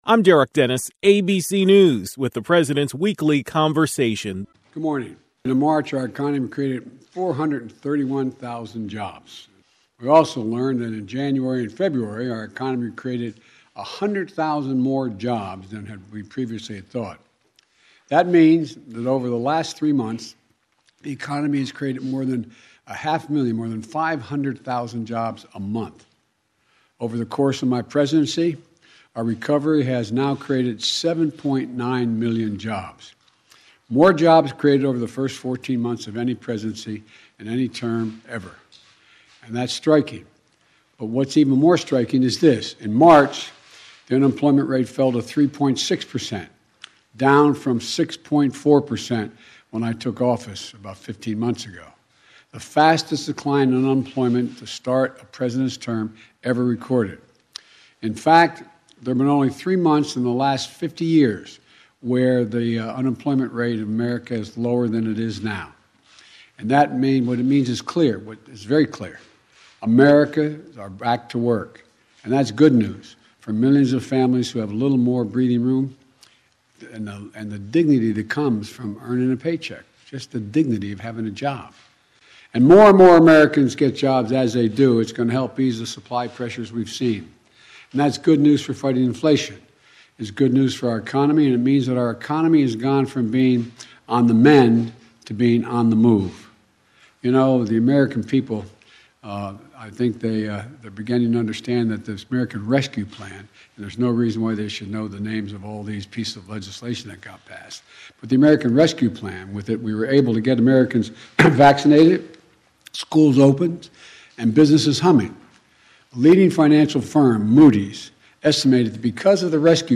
President Biden spoke about the March Jobs Report that was released last Friday.
Biden was Monday’s KVML “Newsmaker of the Day”.